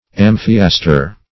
Search Result for " amphiaster" : The Collaborative International Dictionary of English v.0.48: Amphiaster \Am"phi*as`ter\, n. [NL., fr. Gr.